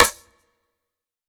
TC2 Snare 25.wav